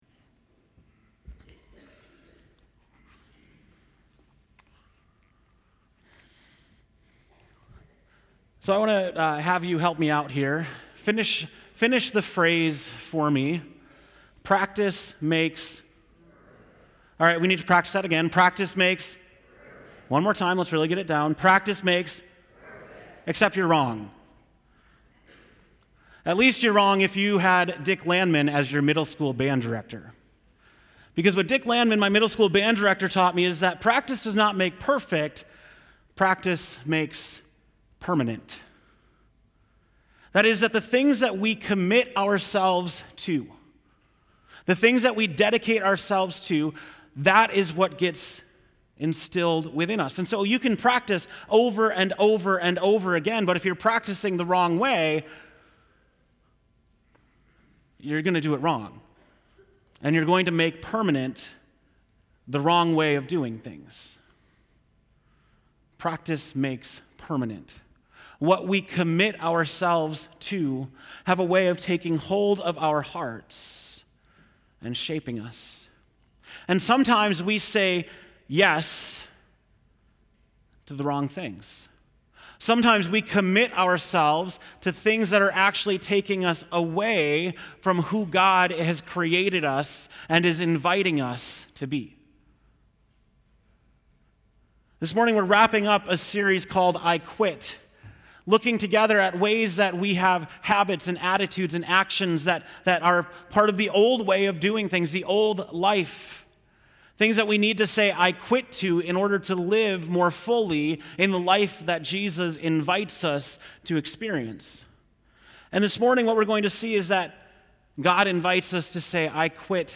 October 27, 2019 (Morning Worship)